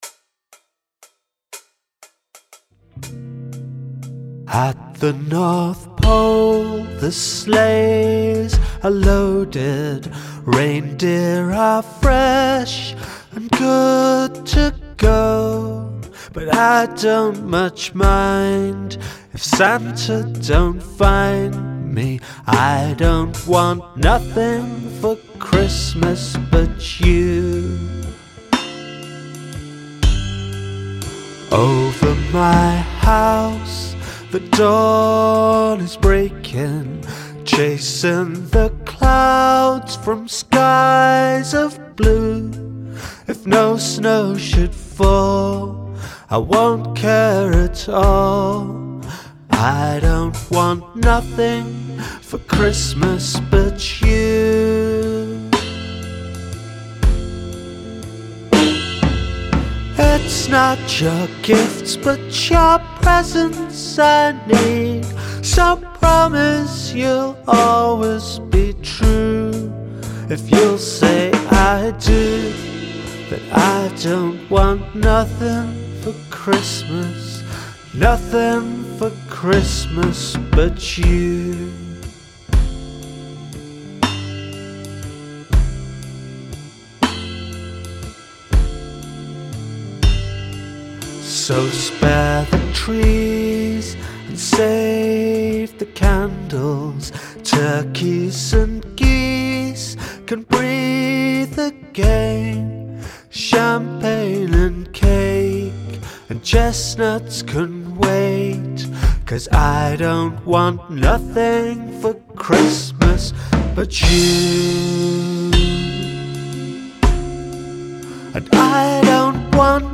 scratch recording:
christmas-demo-at.mp3